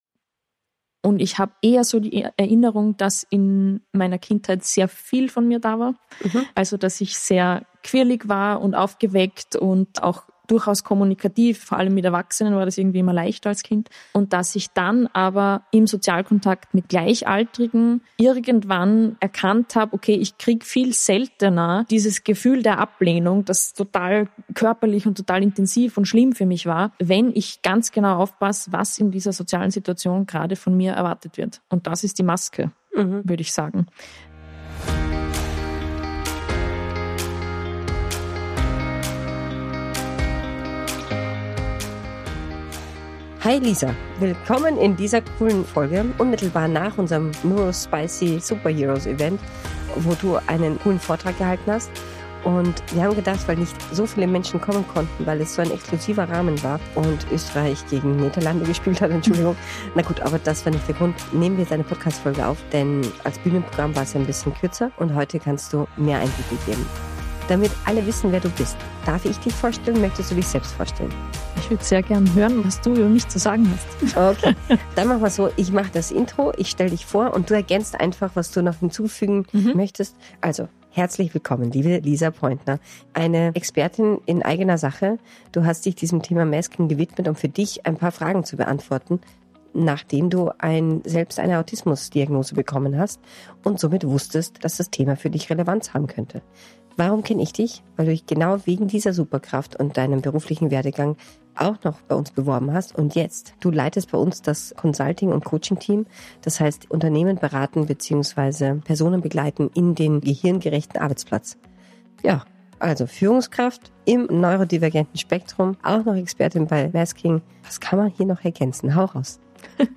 Folge 14: Masking und Autismus: Vom Bauchweh zur Expertin – Ein Gespräch